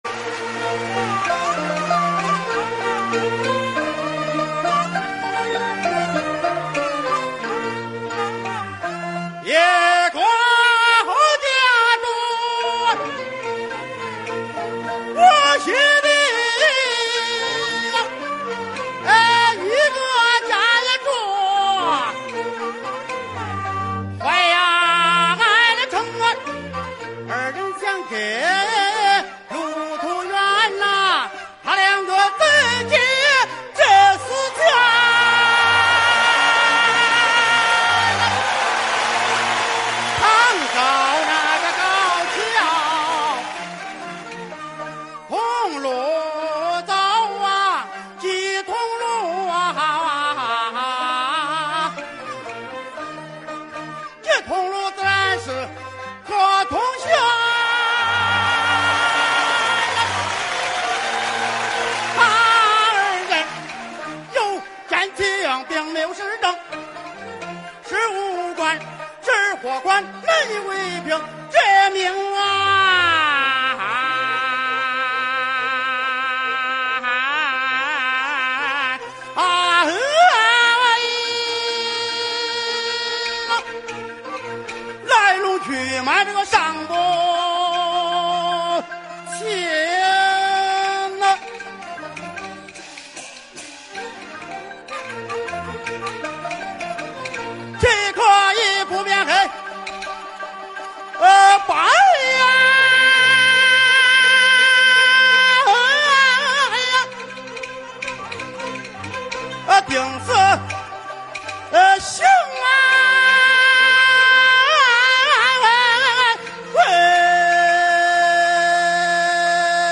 豫剧